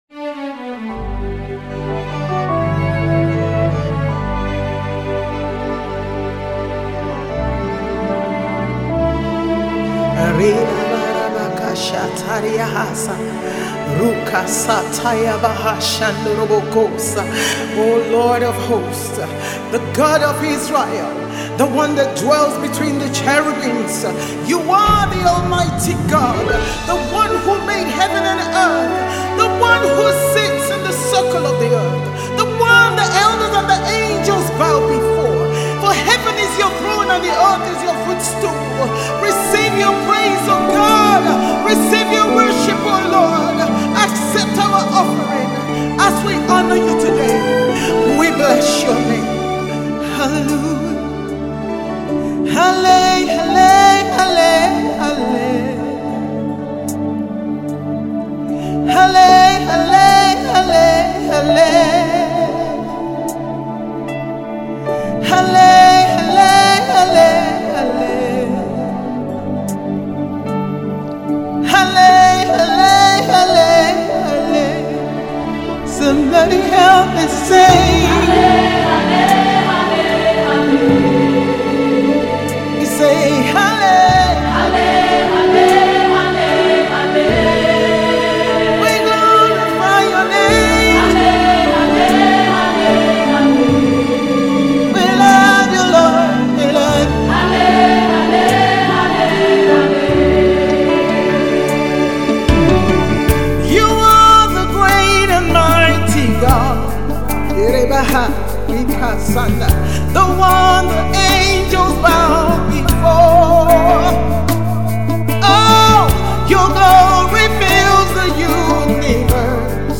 a simple song of worship